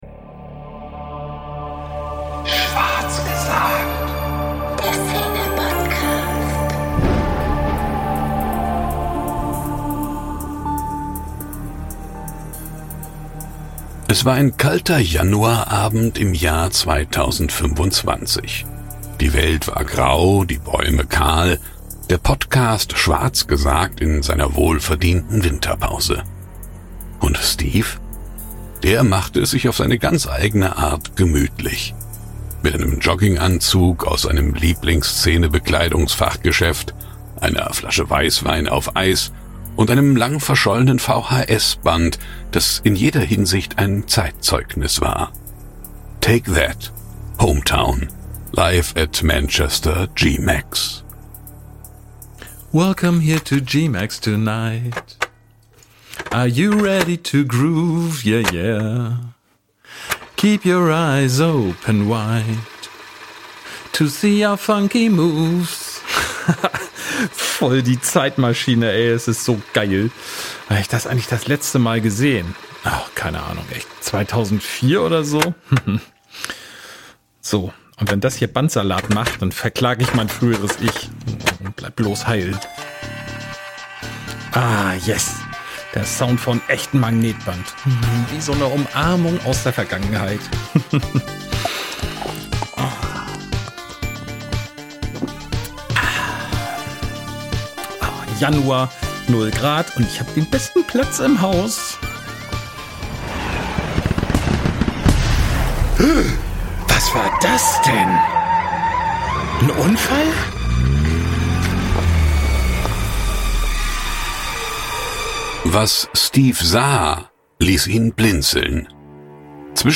ein Zeitreise-Hörspiel zum ersten WGT 1992.